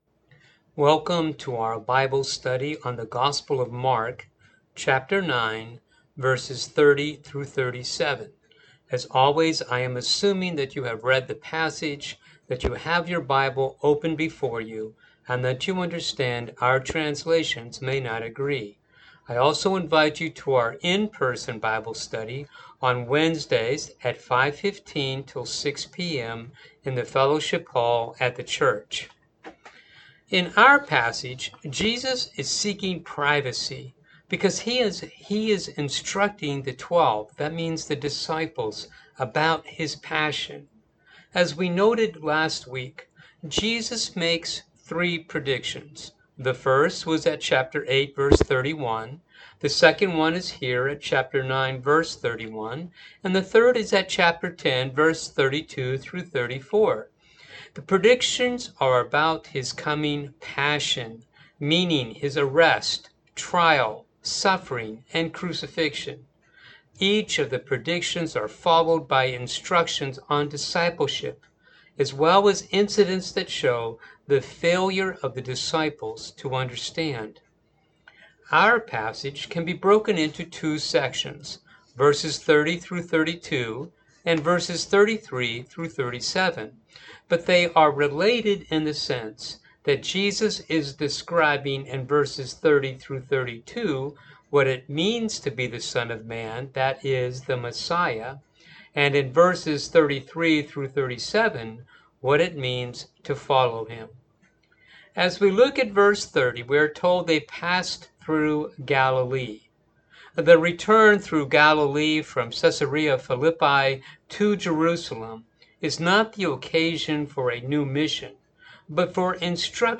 Bible Study for the September 19 Service